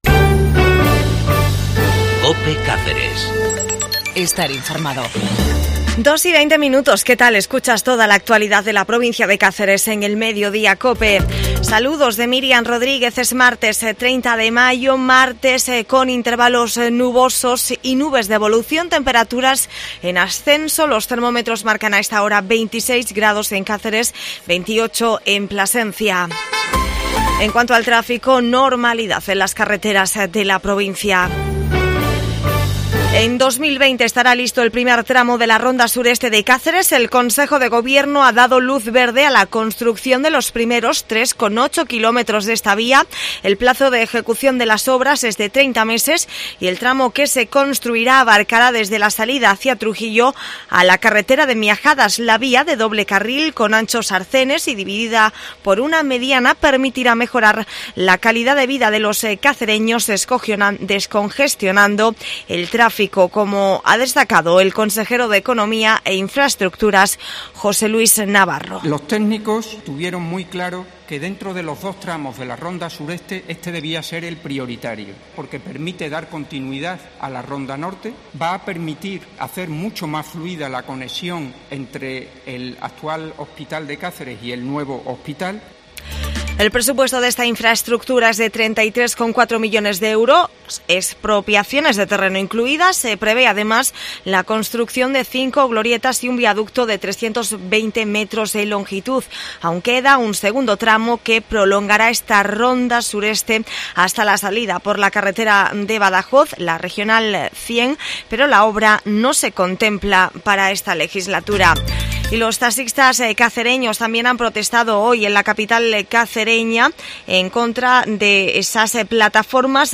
AUDIO: informacion local del 30 de mayo de caceres, cadena cope